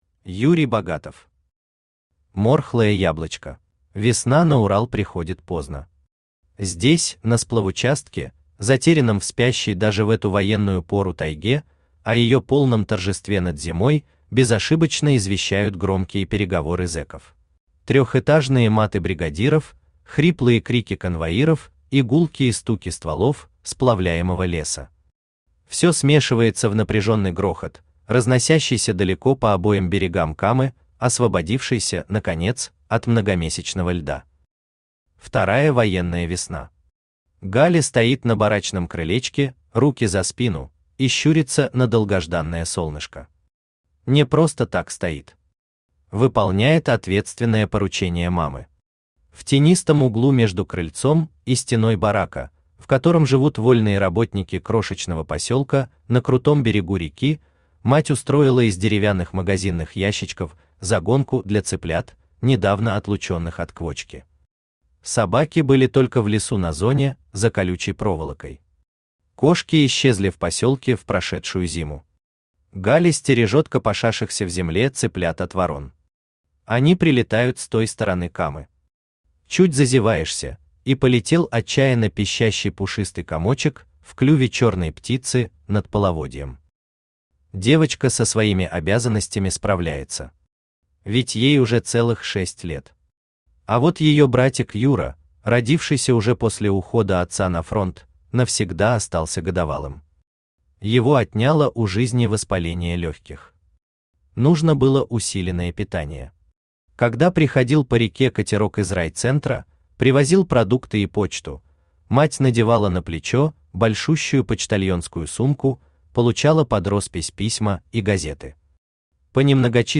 Аудиокнига Морхлое яблочко | Библиотека аудиокниг
Aудиокнига Морхлое яблочко Автор Юрий Анатольевич Богатов Читает аудиокнигу Авточтец ЛитРес.